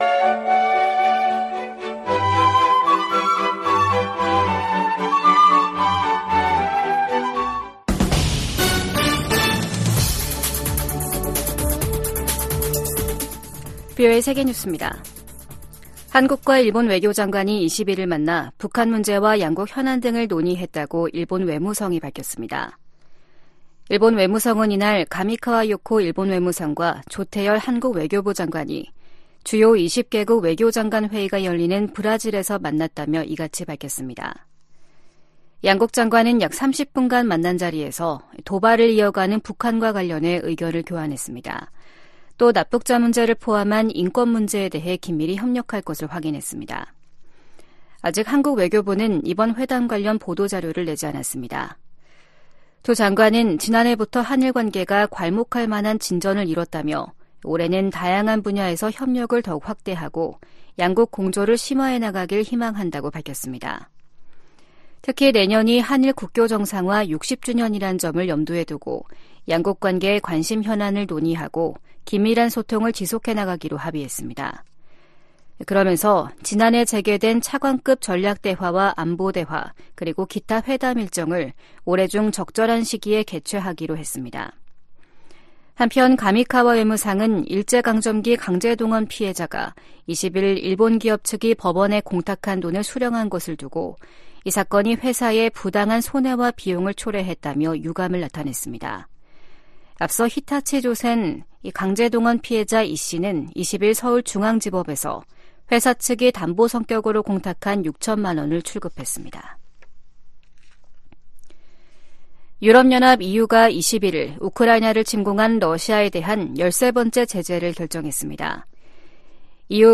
VOA 한국어 아침 뉴스 프로그램 '워싱턴 뉴스 광장' 2024년 2월 22일 방송입니다. 러시아가 우크라이나 공격에 추가로 북한 미사일을 사용할 것으로 예상한다고 백악관이 밝혔습니다. 미국 정부는 북일 정상회담 가능성에 역내 안정에 기여한다면 환영할 일이라고 밝혔습니다.